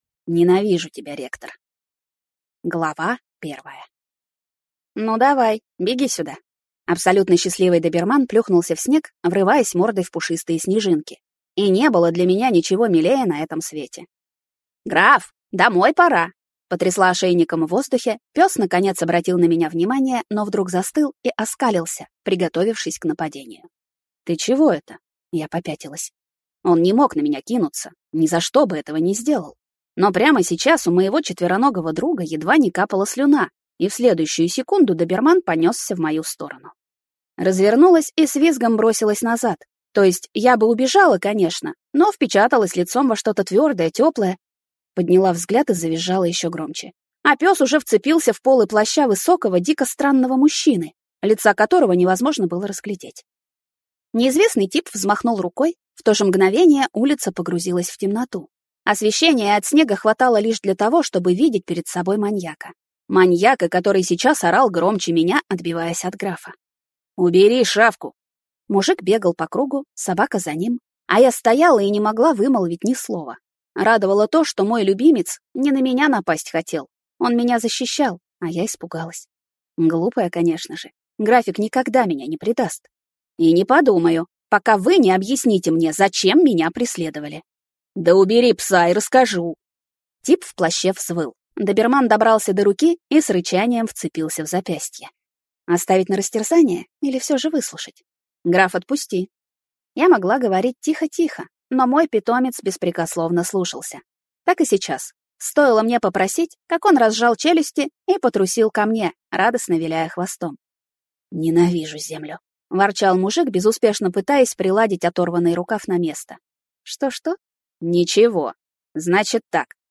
Аудиокнига Драконья академия. Ненавижу тебя, ректор!